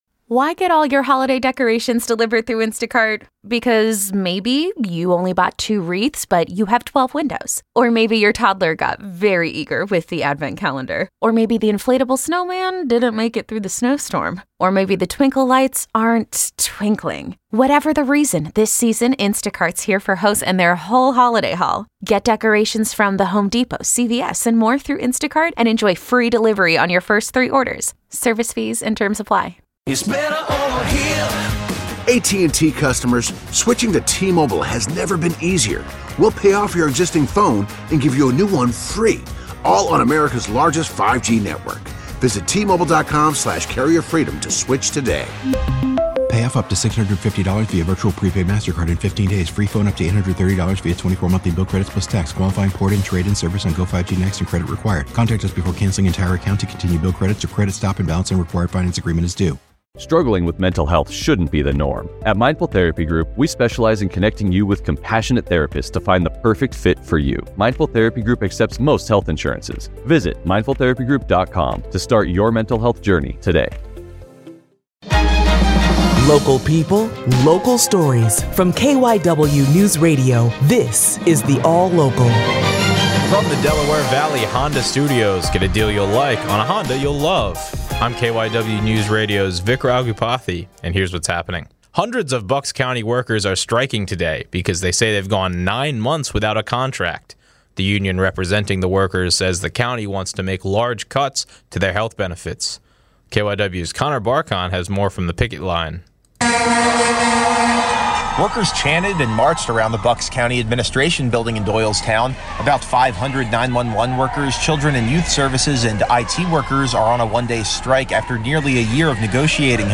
The KYW Newsradio All-Local on Friday, Sep. 20, 2024 (midday edition):